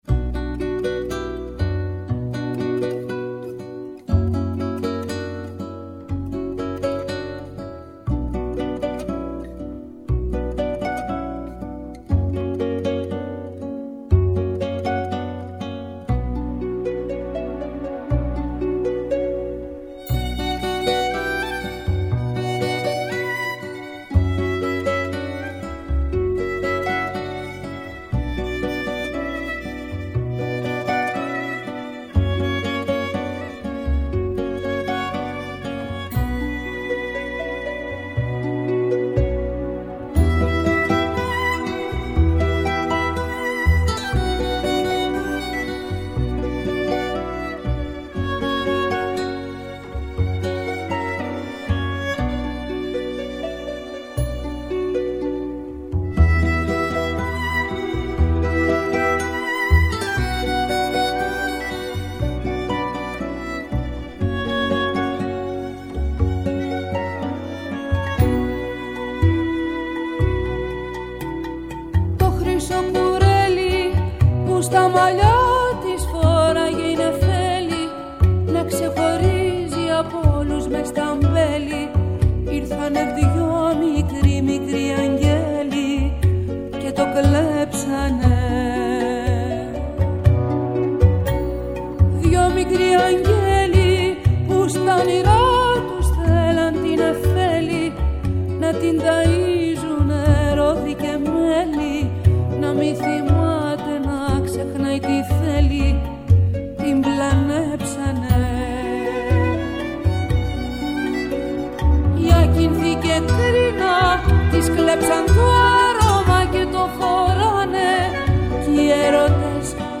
嗓音厚實有些許沙啞